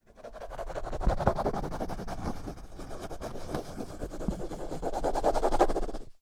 Drawing
aip09 draw drawing folder paper pencil scratch scratching sound effect free sound royalty free Memes